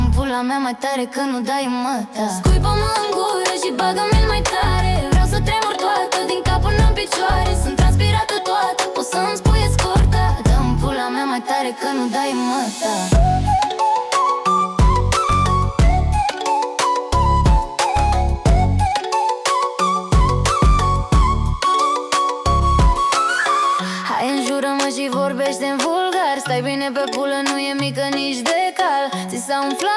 Afro-Pop African R B Soul
Жанр: Поп музыка / R&B / Соул